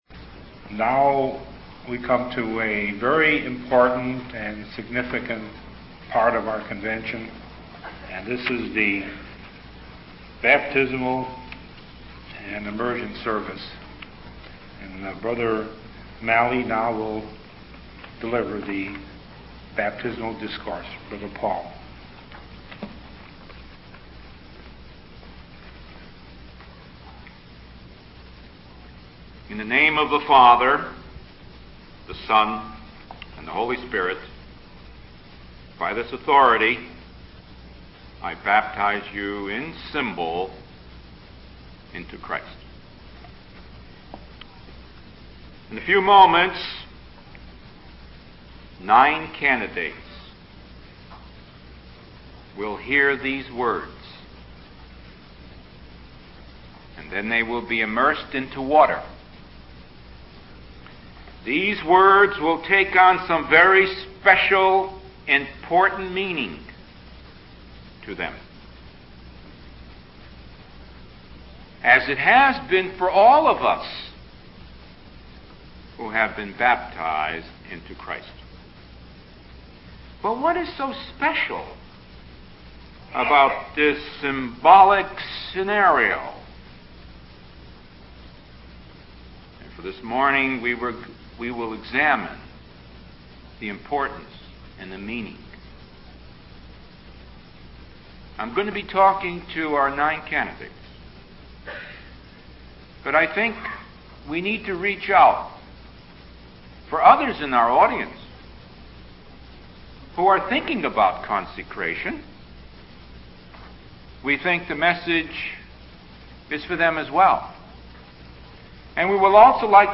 Index of /Chicago_Discourses/1988_Chicago_Memorial_Day_Convention